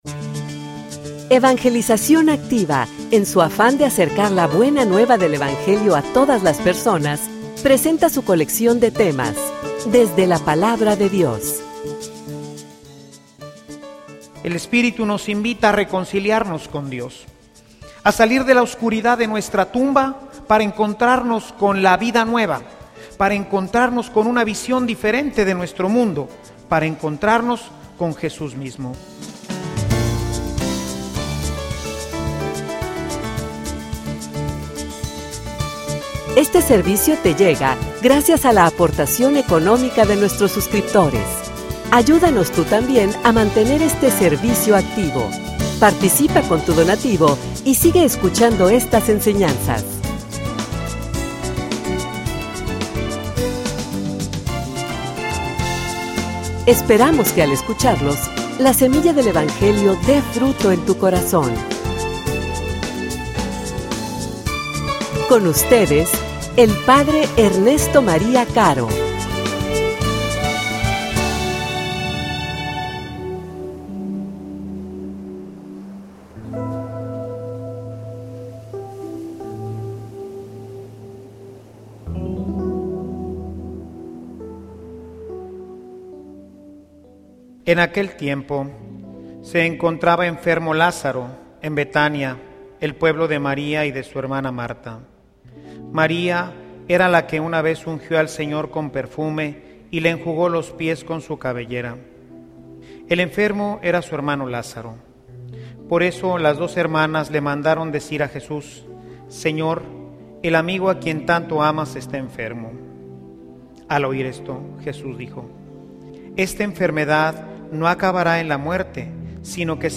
homilia_Lazaro_sal_fuera.mp3